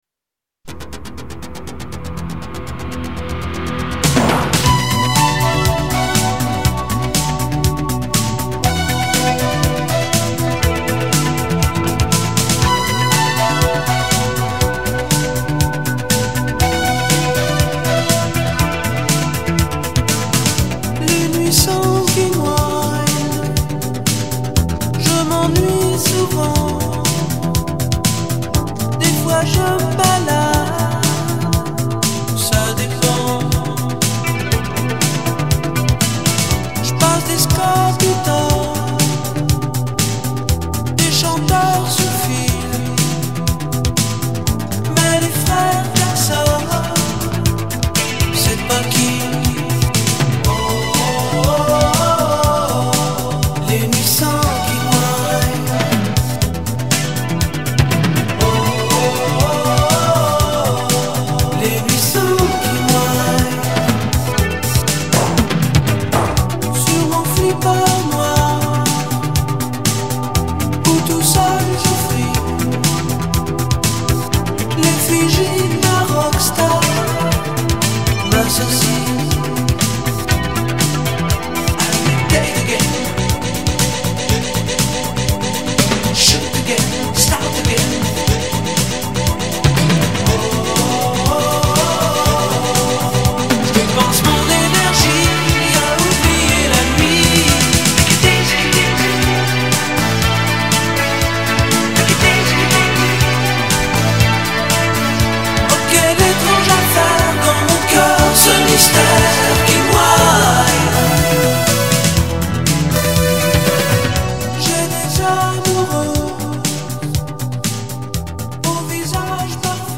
et ces chœurs féminins…